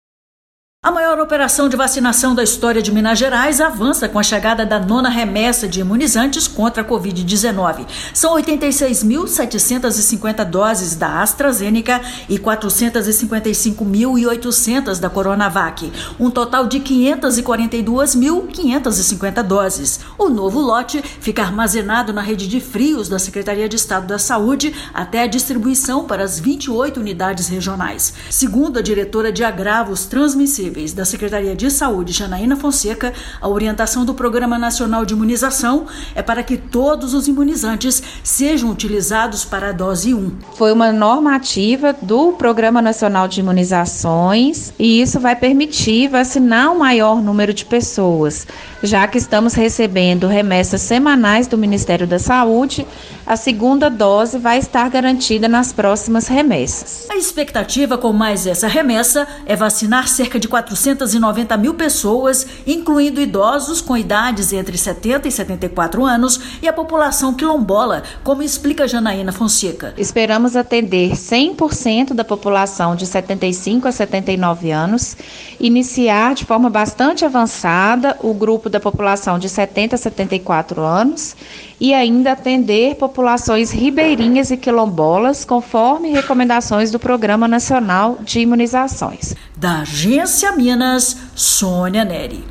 São 86.750 doses da AstraZeneca e 455.800 da CoronaVac, totalizando 542.550 doses. Ouça a matéria de rádio.